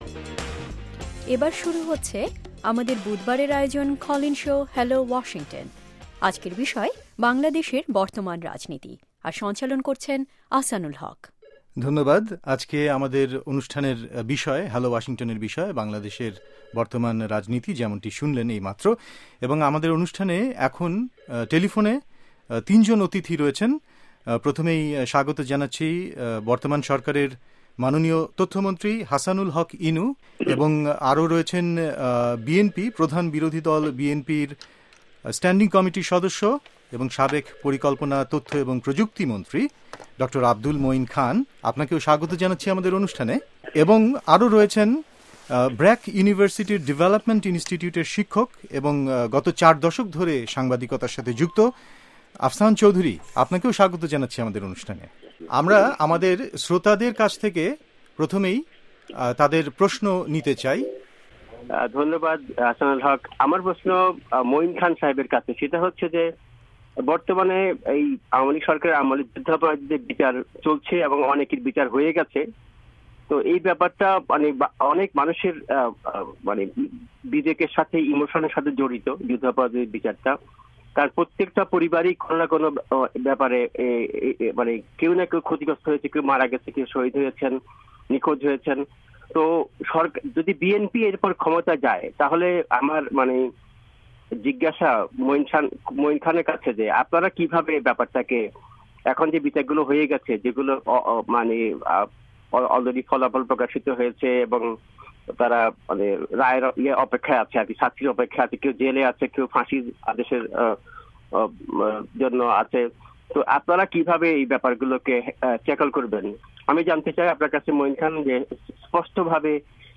বাংলাদেশের উত্তপ্ত রাজনৈতিক পরিস্থিতি নিয়ে এ সপ্তাহের হ্যালো ওয়াশিংটনে শ্রোতাদের প্রশ্নের জবাব দিয়েছেন তথ্যমন্ত্রী হাসানুল হক ইনু